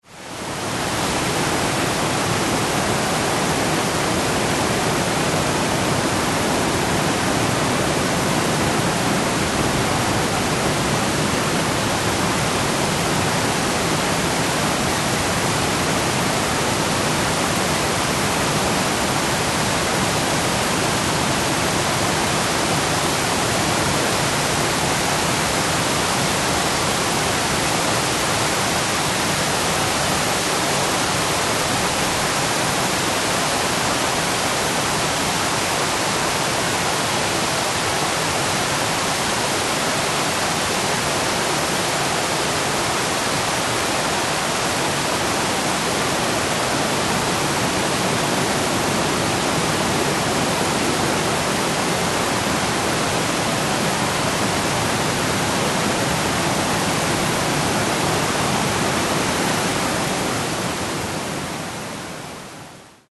Звук сильного ливня у озера или реки